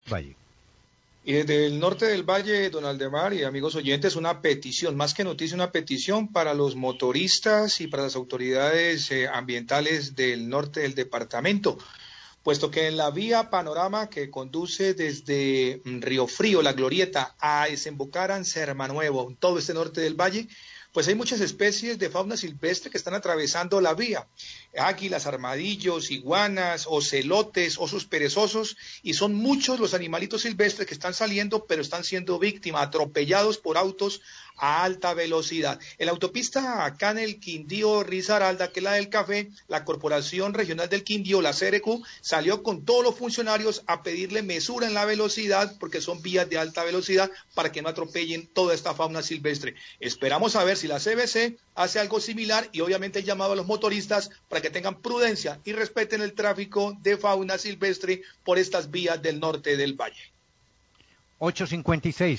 Radio
Periodista hace un llamado a los conductores para disminuir la velocidad en las carreteras debido al alto número de animales silvestres que están saliendo durante la emergencia sanitaria y cruzando las vías.  Indica que la CRQ está saliendo a las vías a advertir a los conductores para que reduzcan la velocidad y así evitar atropellar animales.